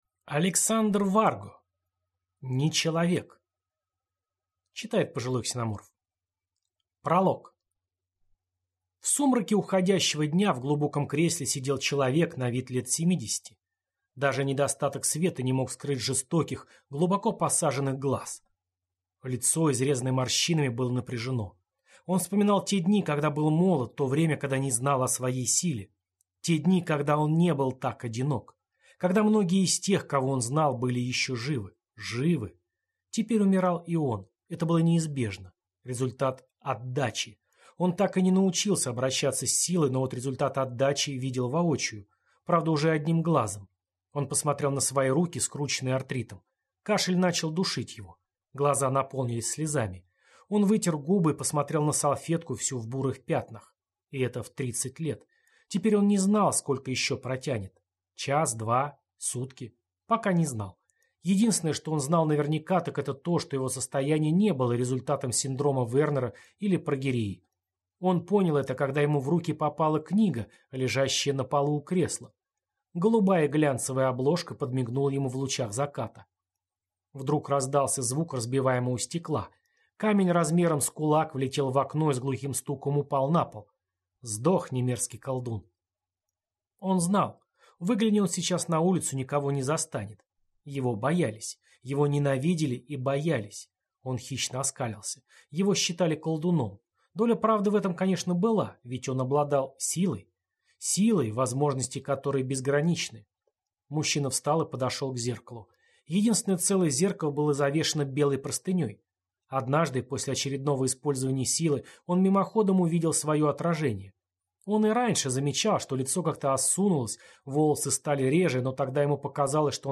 Аудиокнига Нечеловек | Библиотека аудиокниг